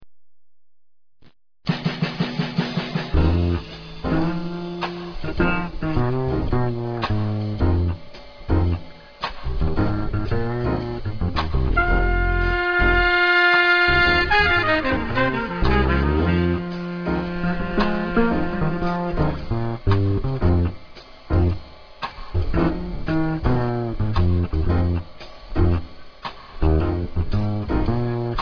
tenor players